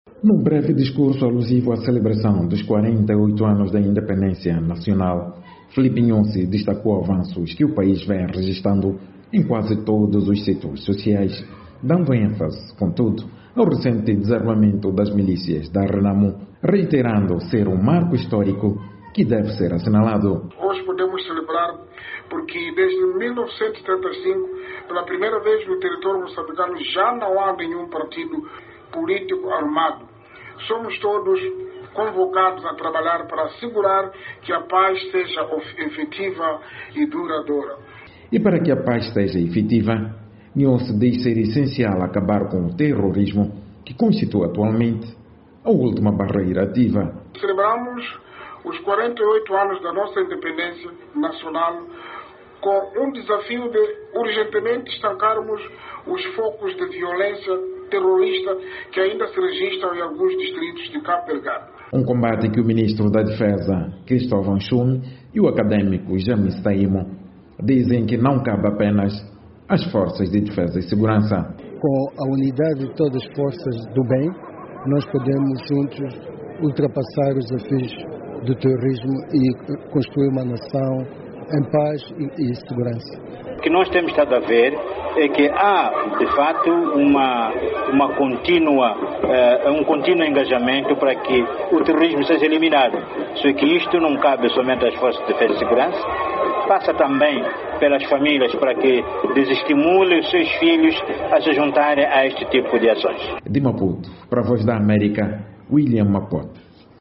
Num discurso alusivo à celebração da efeméride, Filipe Nyusi destacou o crescimento comparativo nos sectores da educação, saúde, justiça e produção alimentar, mas reconheceu a persistência de desafios por ultrapassar.